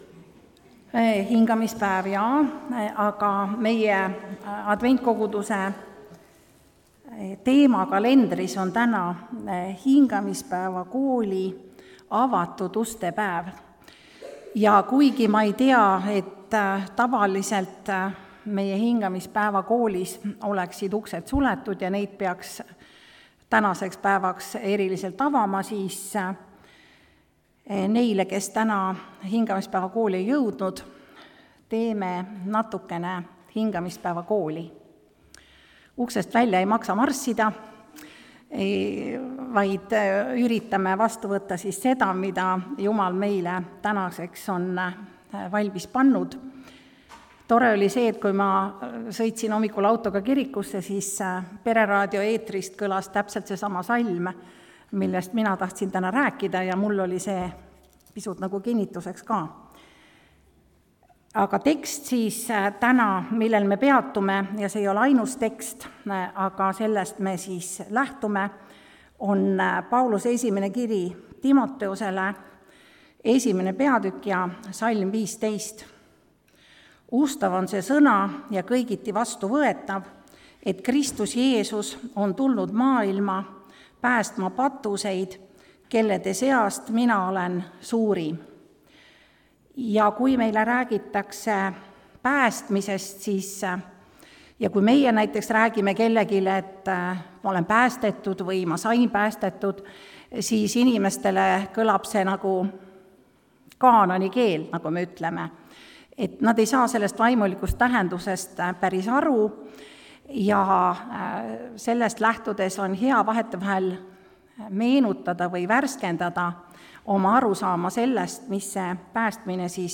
Suurima patuse päästmine (Tallinnas)
Jutlused